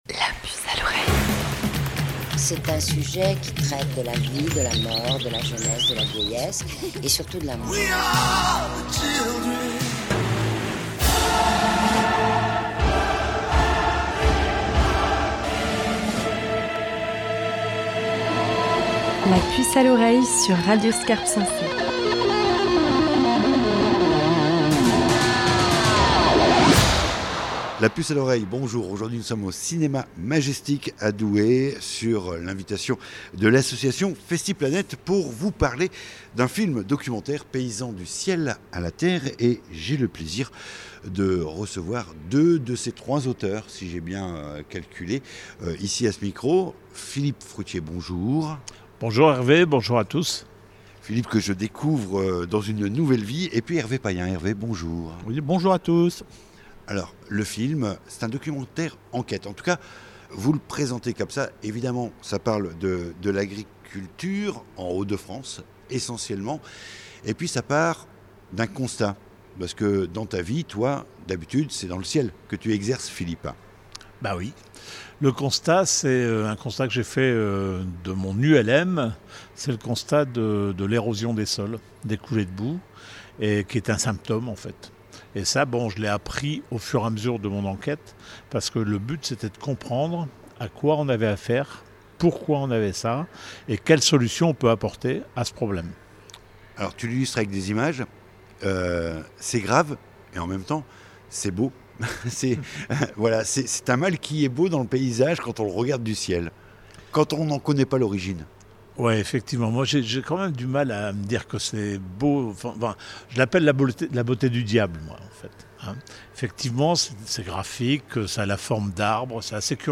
Interview Radio Scarpe Sensée